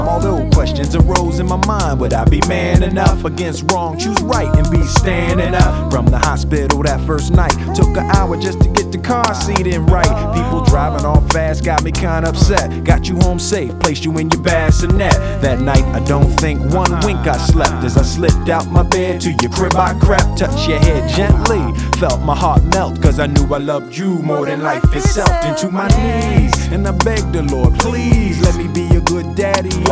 • Hip-Hop/Rap
For his album, he performed it in rap form.